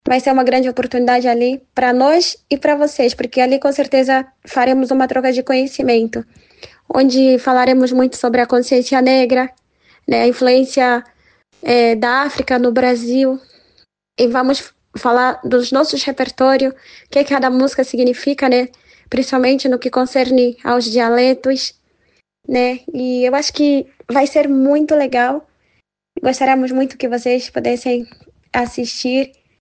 SONORA-CONCERTO-ANGOLA-01-BO.mp3